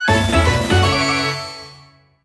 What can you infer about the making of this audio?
Ripped from the game